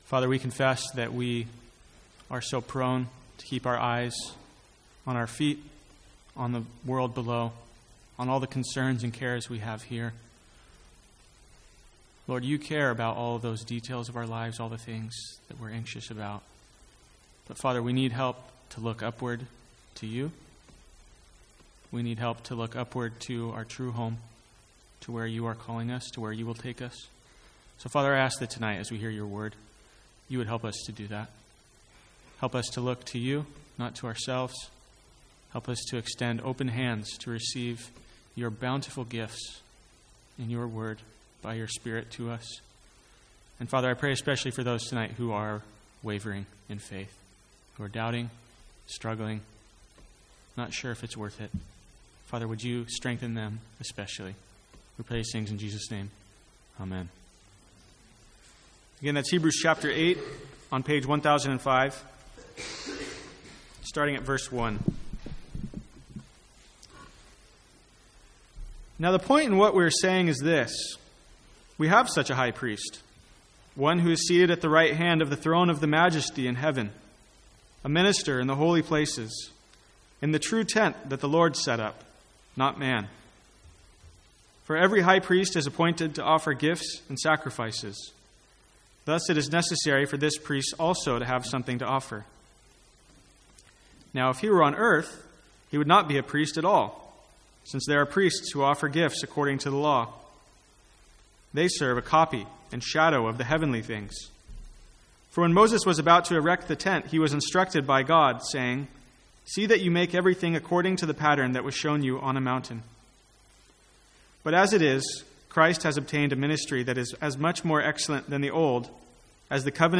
Sermons | St Andrews Free Church
From the Sunday evening series in Hebrews.